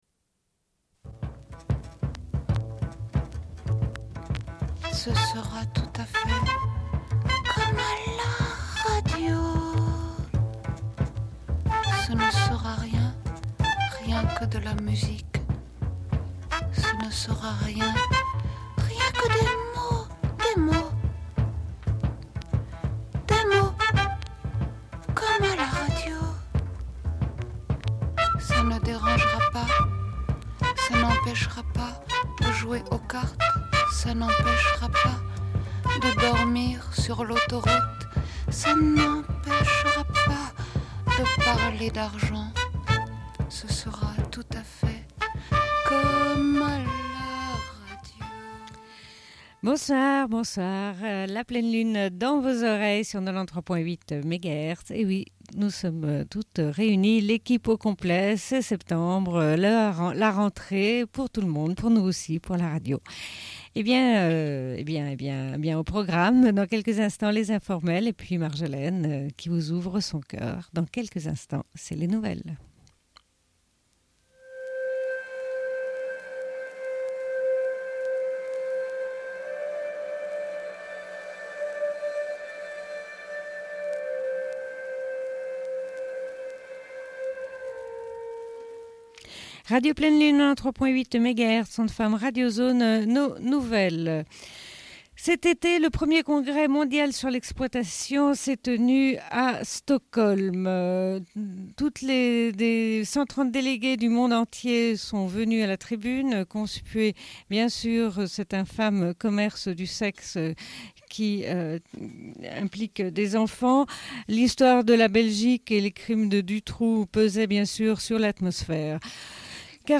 Bulletin d'information de Radio Pleine Lune du 04.09.1996 - Archives contestataires
Une cassette audio, face B